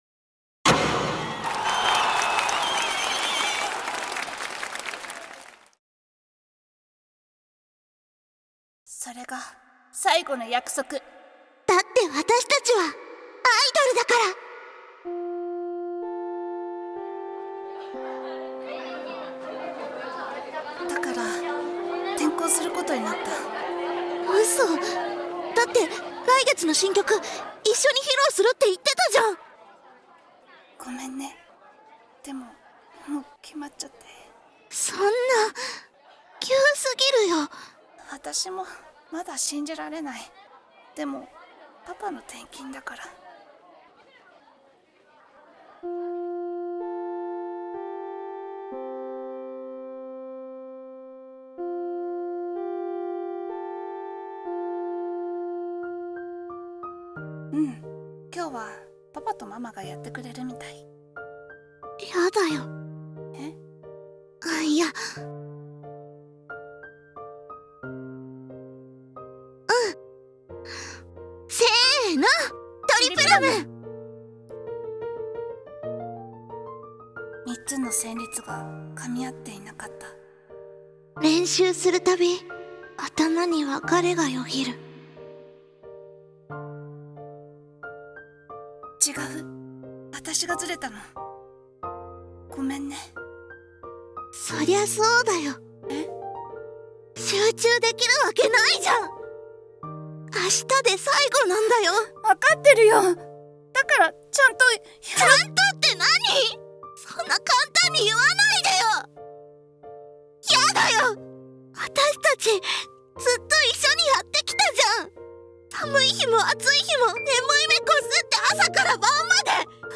【アイドル声劇】とりぷらむっ！